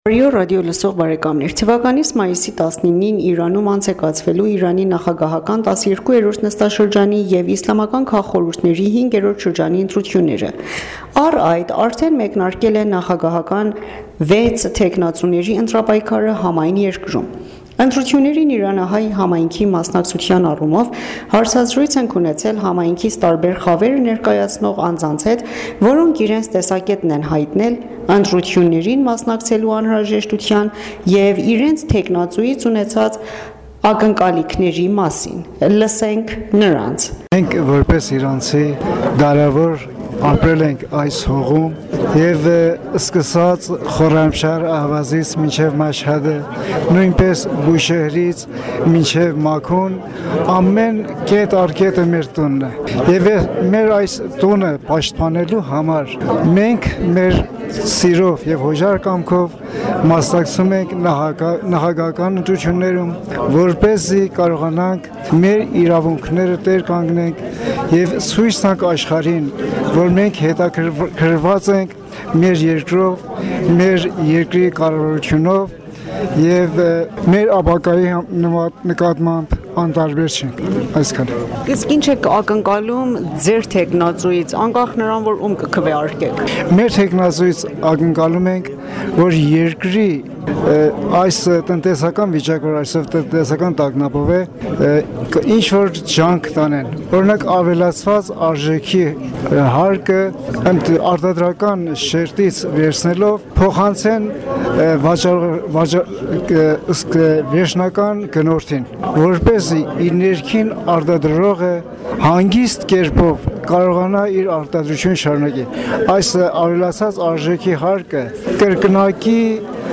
Հարցազրույց՝ Իրանահայ համայնքի տարբեր խավերի ներկայացուցիչների հետ առաջիկա ընտրությունների վերաբերյալ